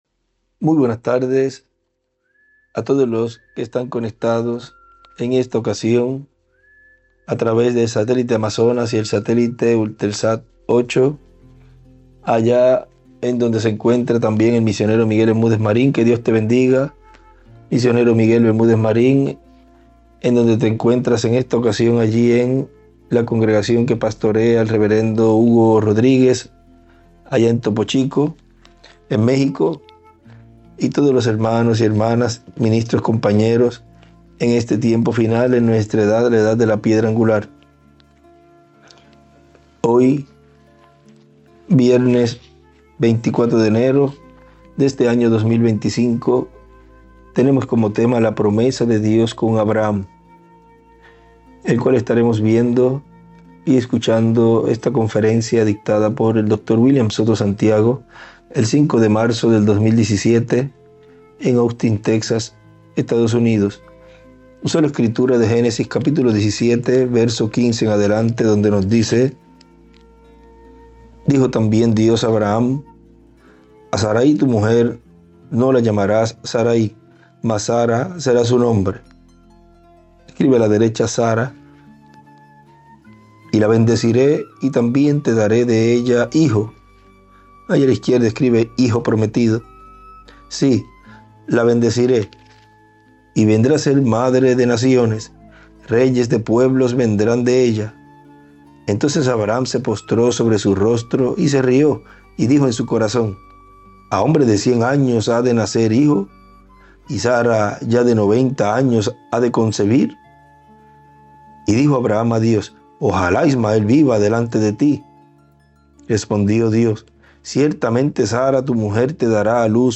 ESTUDIO BÍBLICO #484VIERNES, 24 DE ENERO DE 2025Cayey, Puerto Rico